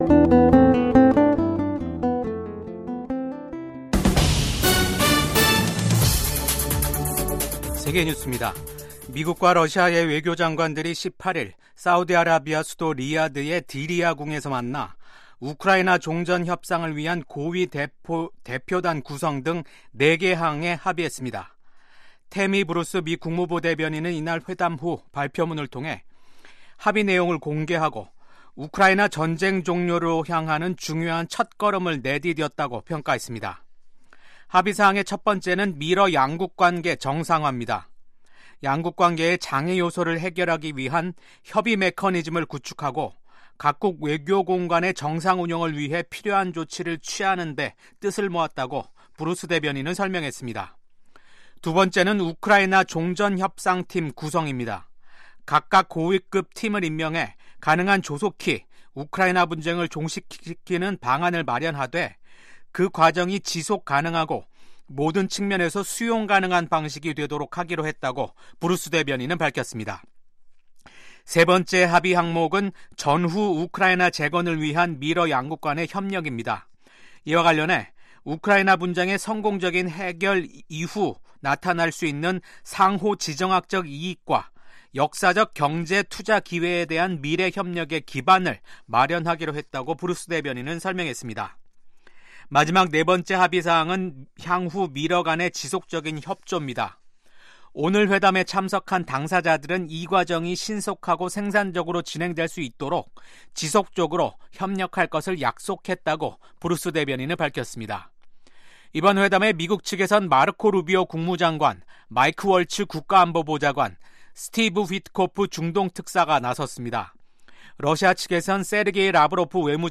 VOA 한국어 아침 뉴스 프로그램 '워싱턴 뉴스 광장'입니다. 미국 정부가 북한의 핵·미사일 위협을 비판하며 한국, 일본 등 동맹과 긴밀히 협력하고 있다고 밝혔습니다. 한국이 유엔 안보리 회의에서 러시아에 병력을 파병한 북한을 강하게 규탄했습니다. 북한은 미한일 외교장관들이 북한의 완전한 비핵화를 명시한 공동성명을 발표한 데 대해 반발하는 담화를 냈습니다.